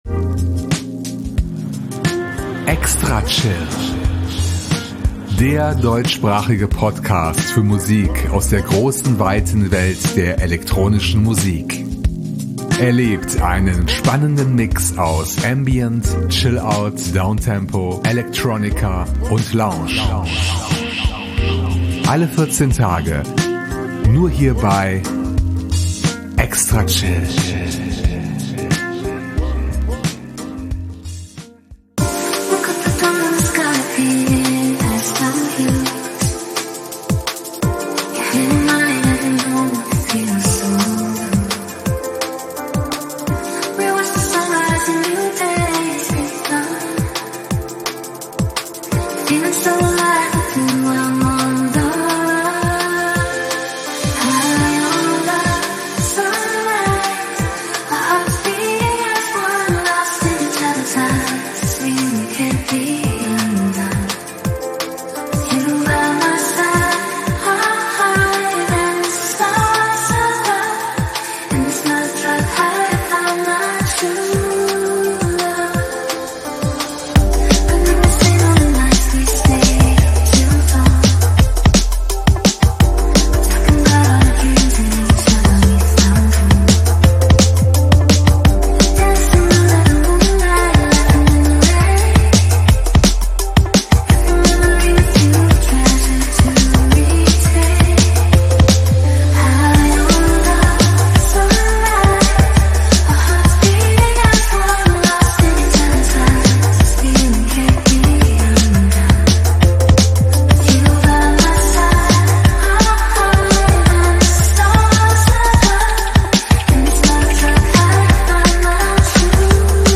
So klingt der Sommer - ganz entspannt!